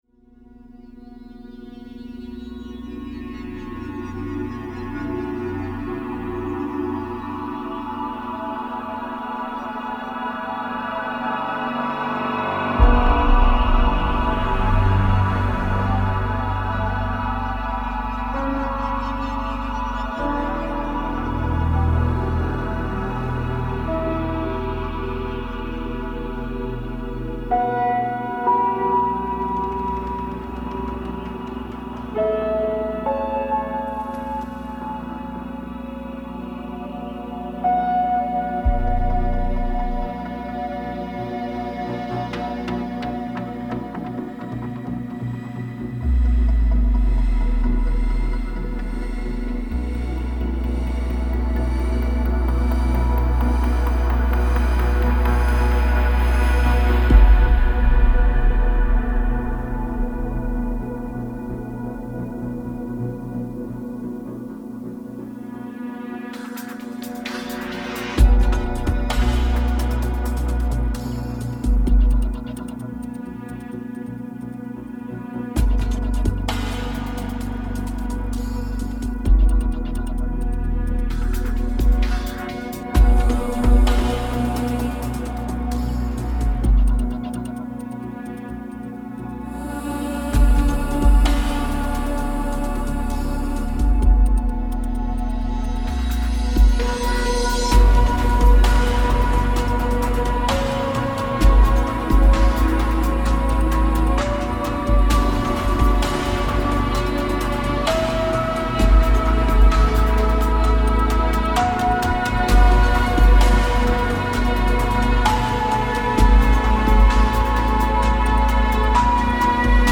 Domeniul de frecvență: Gamma